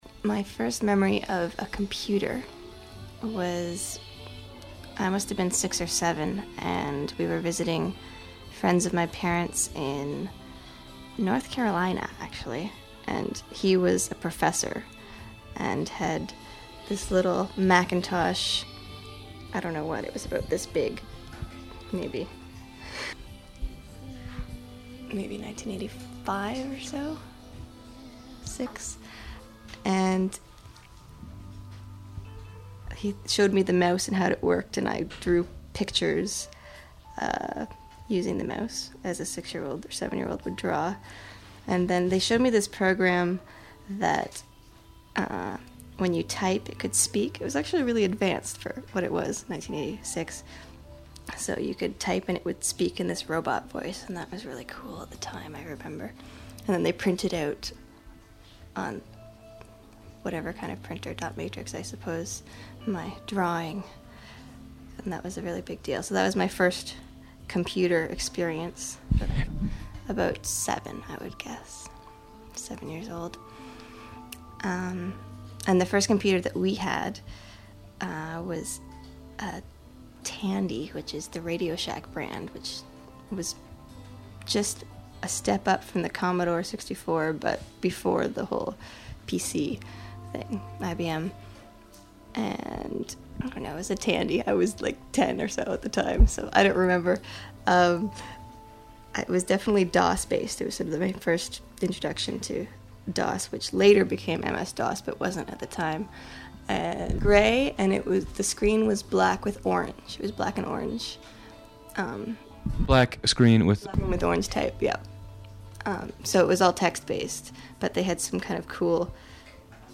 Edition: 10,000 interviews
The First Contact Project seeks to capture these memories for posterity in the oldest and most vernacular human form: oral storytelling, in order to create a collective consciousness and institutional memory of human beings' first contact with the digital age.